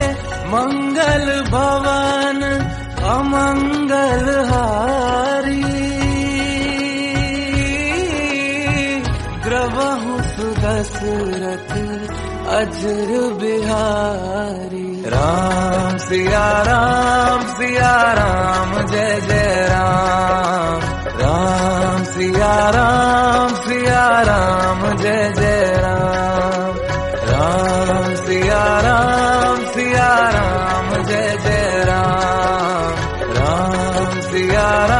जिसमे भगवान श्रीराम और माता सीता के नाम का जाप किया गया है।